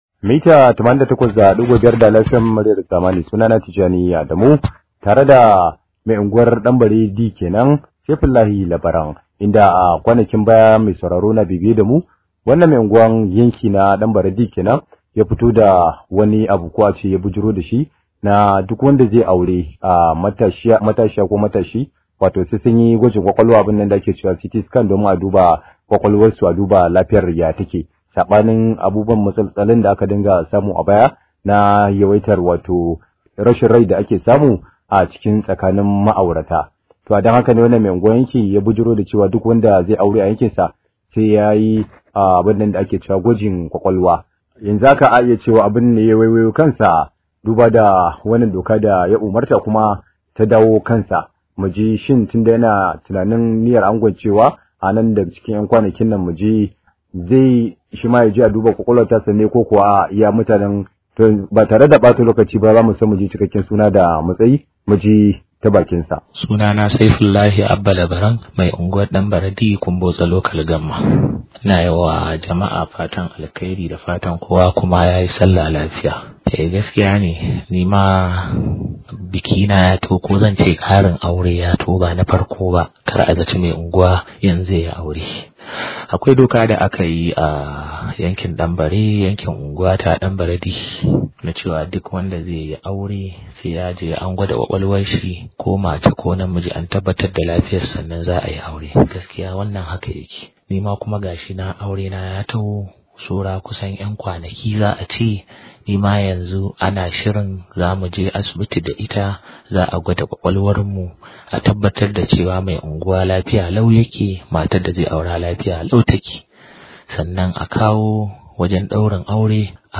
Rahoto: Mai Unguwa zai yi gwajin kwakwalwa kafin ya angwance